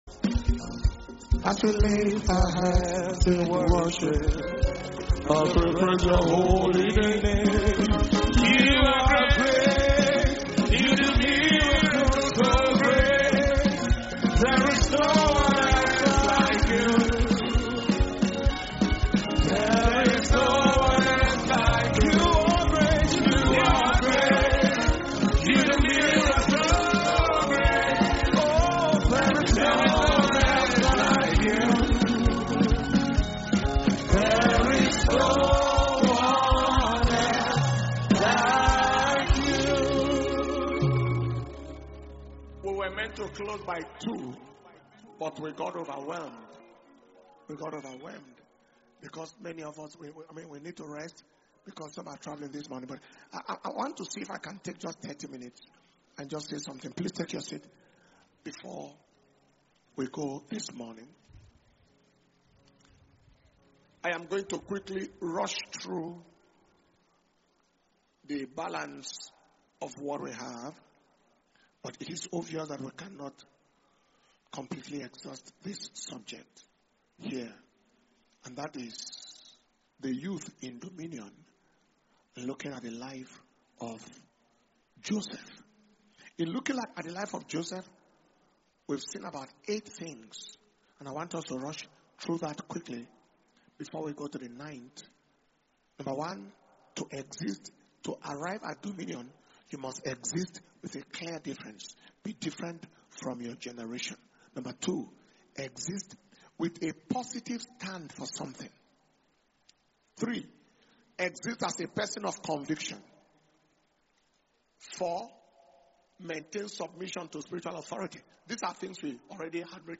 Dunamis Ignite Conference August 2025 – Day Five Evening Session